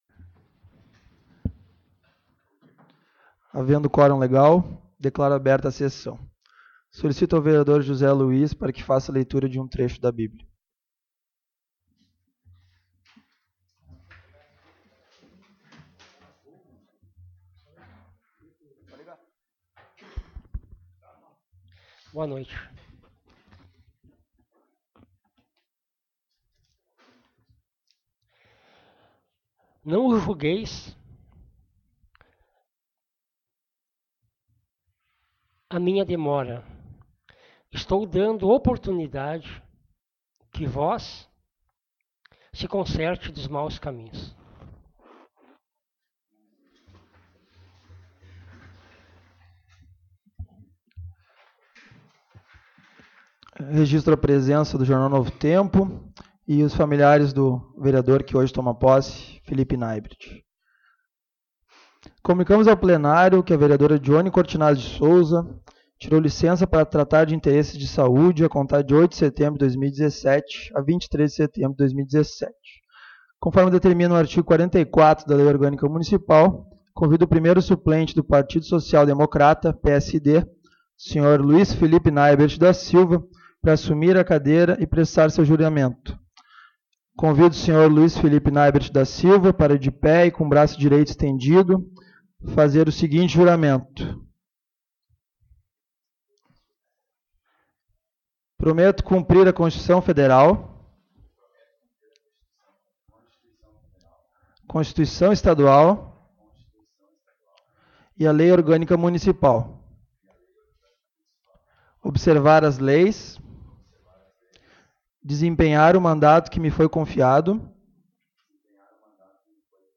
Publicação: 24/02/2021 às 11:06 Abertura: 24/02/2021 às 11:06 Ano base: 2017 Número: Palavras-chave: Anexos da publicação Áudio da Sessão Ordinária de 14.09.2017 às 19 h. 24/02/2021 11:06 Compartilhar essa página...